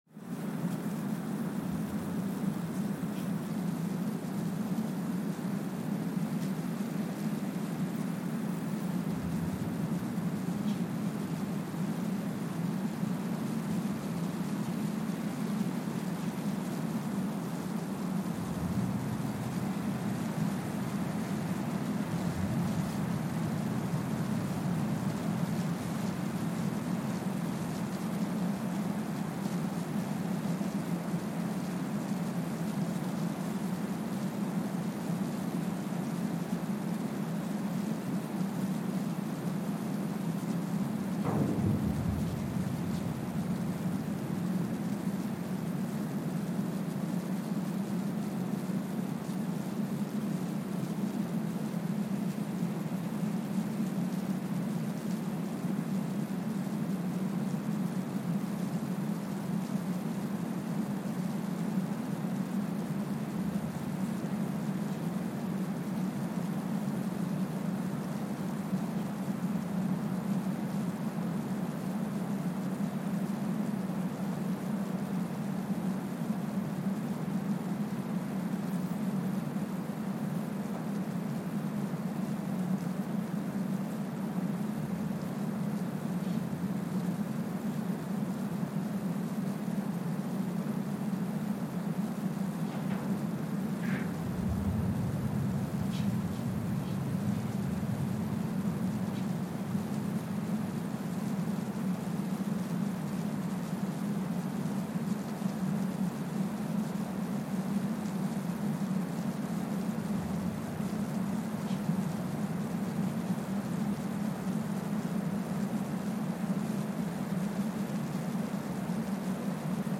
Kwajalein Atoll, Marshall Islands (seismic) archived on October 7, 2023
Sensor : Streckeisen STS-5A Seismometer
Speedup : ×1,000 (transposed up about 10 octaves)
Loop duration (audio) : 05:45 (stereo)
Gain correction : 25dB